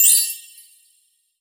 chimes_magical_bells_08.wav